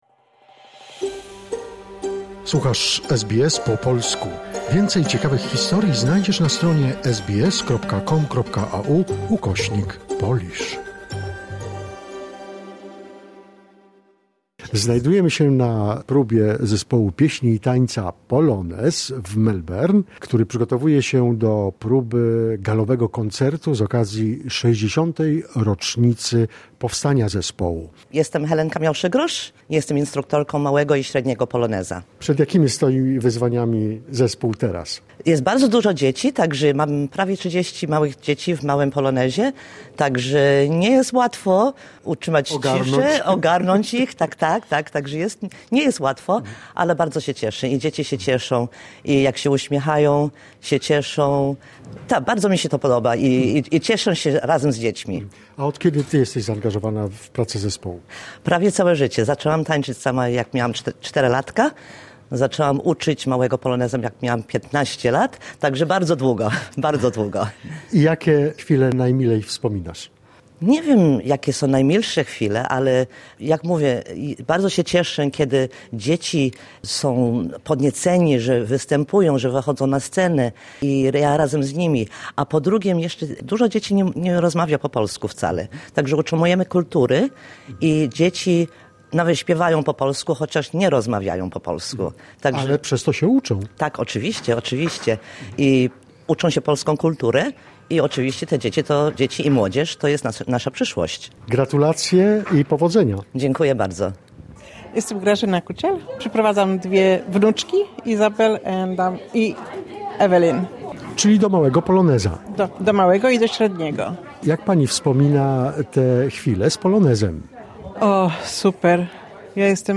Tancerze zespołu pieśni i tańca 'Polonez' z Melbourne opowiadają o pracy w grupie i wyzwaniach, z jakimi polonijny zespół mierzy się obecnie.